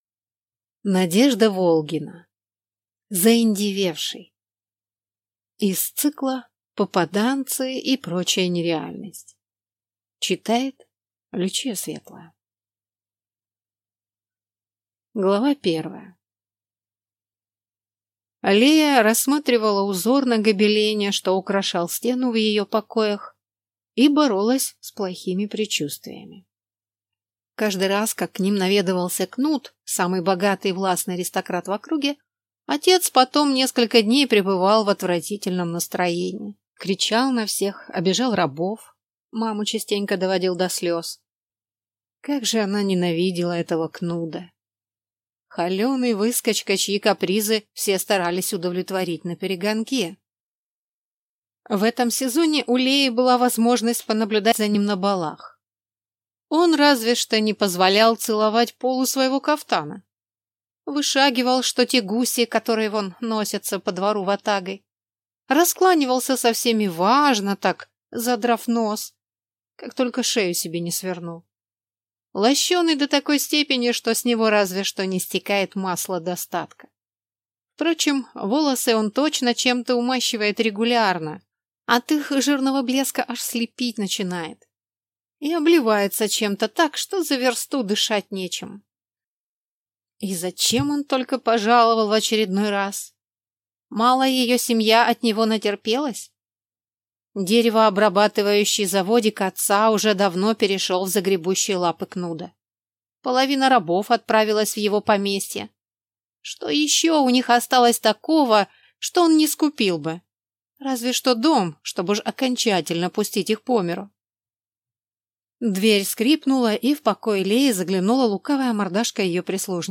Аудиокнига Заиндевевший | Библиотека аудиокниг
Прослушать и бесплатно скачать фрагмент аудиокниги